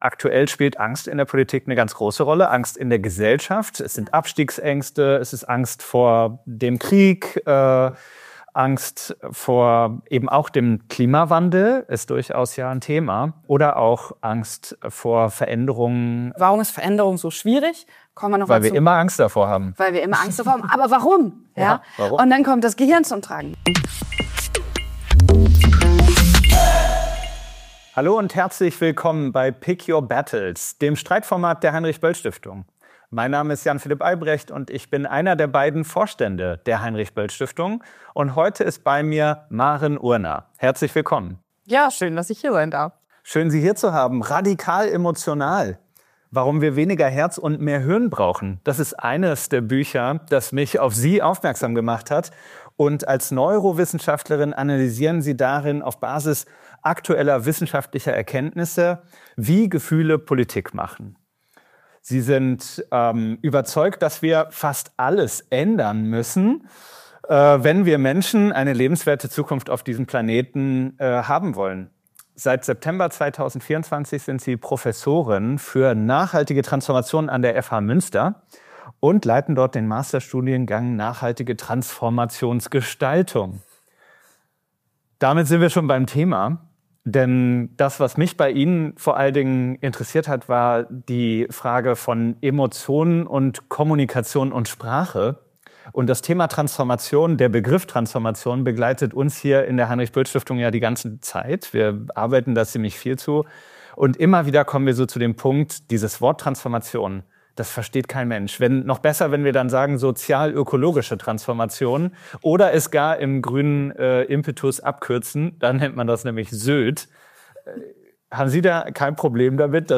Maren Urner im Gespräch mit Jan Philipp Albrecht über emotionale Reife, Klimaschutz, Angst, Transformation und Egoismus.
Eine konstruktive Debatte über grüne Politik, über kollektive Verantwortung – und über die Frage: Wie schaffen wir ein Verständnis für Veränderungen, das die Menschen verstehen und akzeptieren?